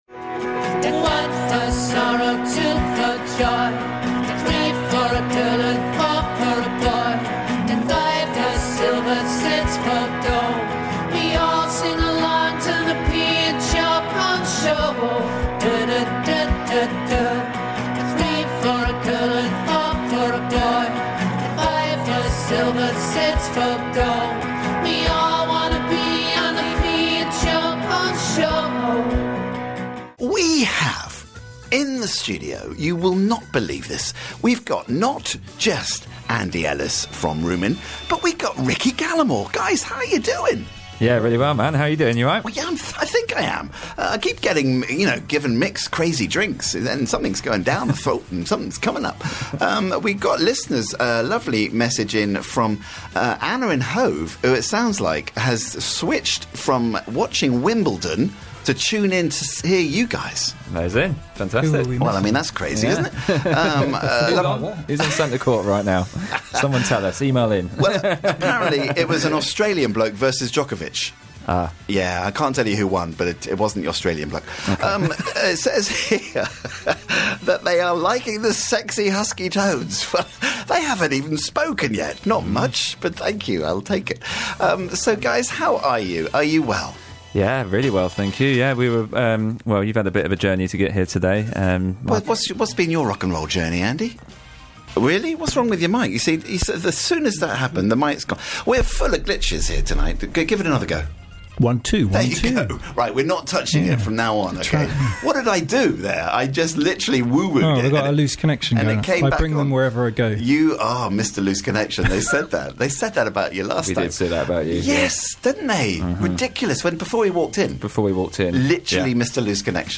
sounding brilliant as an acoustic duo
Great chat, great banter, great live tunes: